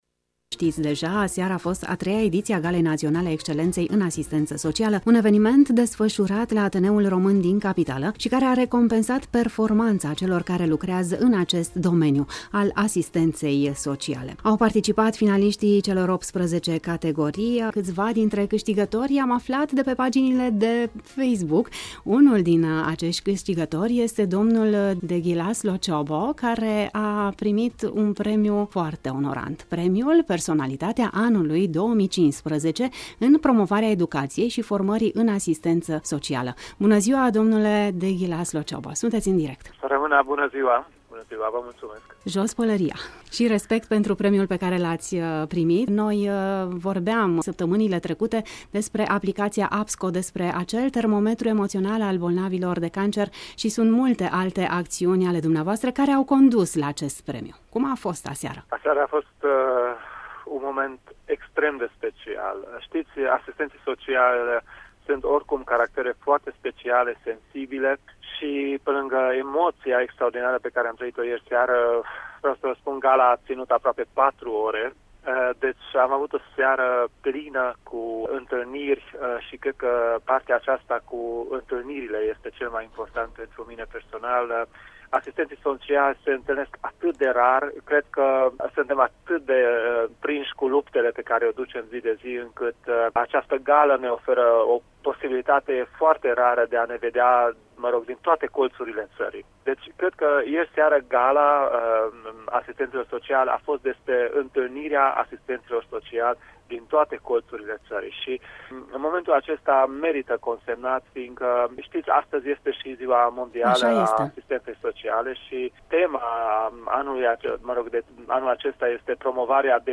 în emisiunea ”Pulsul zilei”, la Radio Tg.Mureș, chiar cu ocazia Zilei Mondiale a Asistenței Sociale: